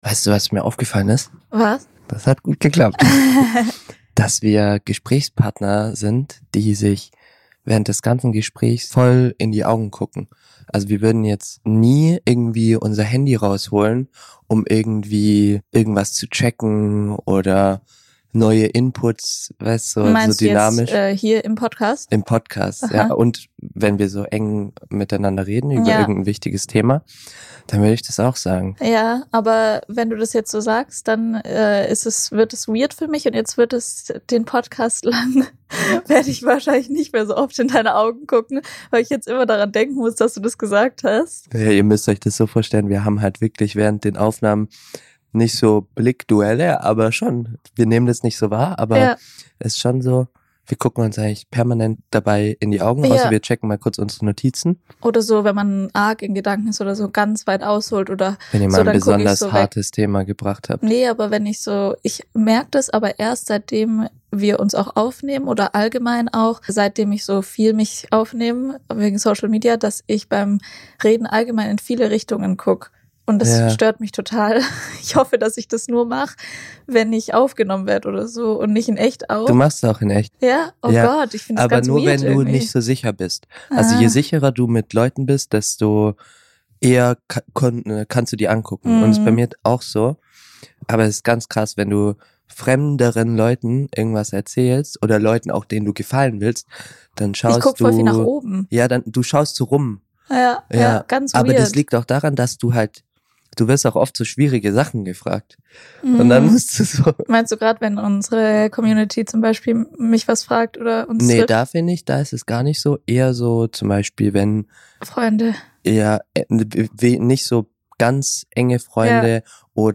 Wieso können wir manchmal Kleinigkeiten hinter uns lassen und in manchen Wochen fällt uns das unglaublich schwer? Sogar live in der Folge fangen wir wieder an zu diskutieren.